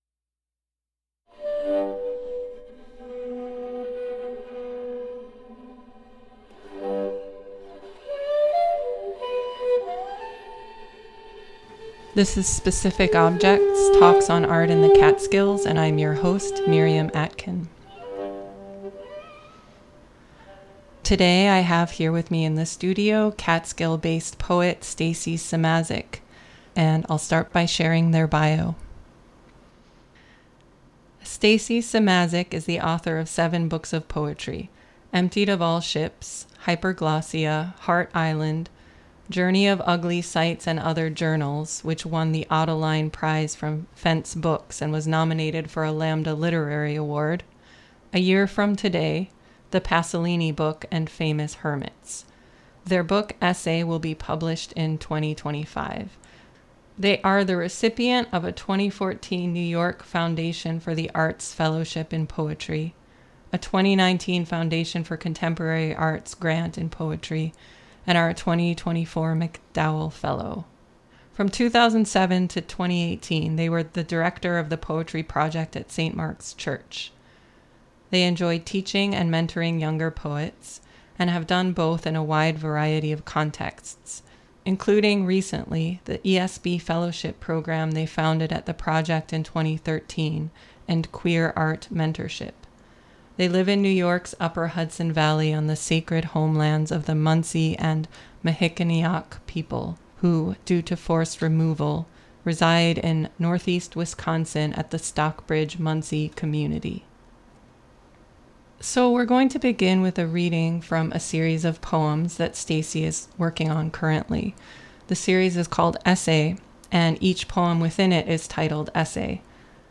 ~ Specific Objects is a monthly freeform discussion